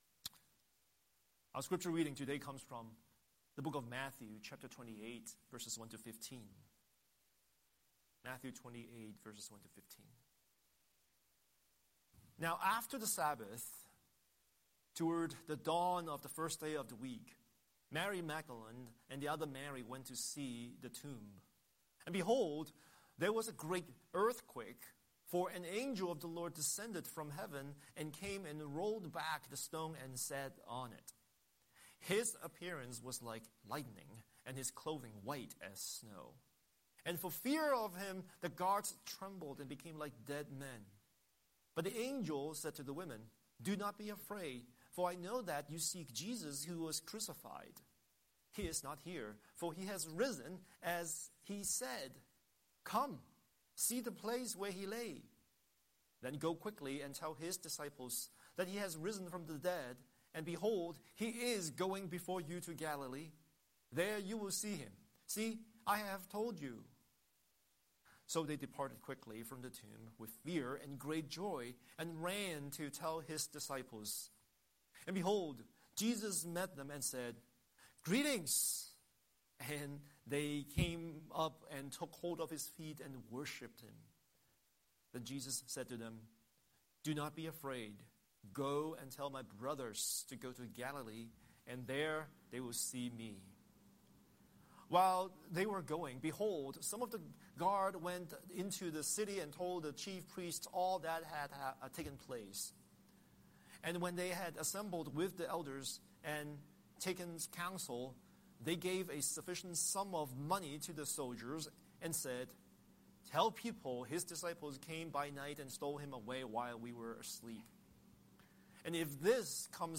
Series: Sunday Sermon